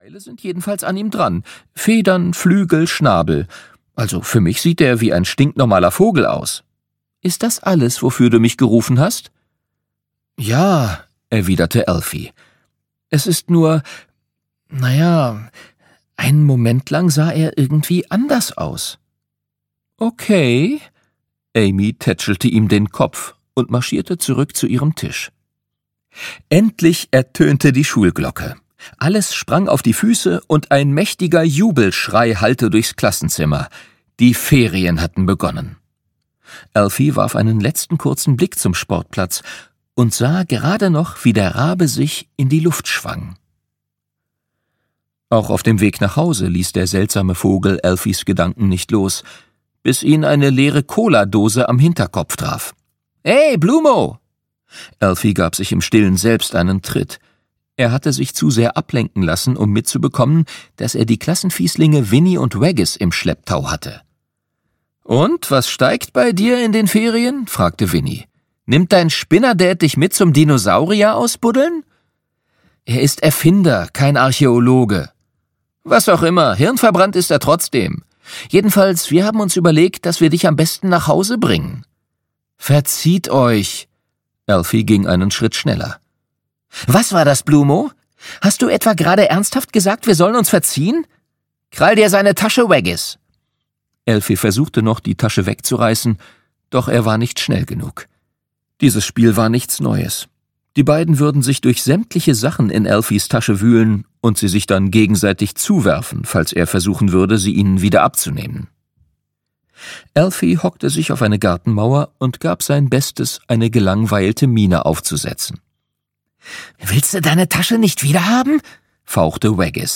Alfie Bloom 1: Das Geheimnis der Drachenburg - Gabrielle Kent - Hörbuch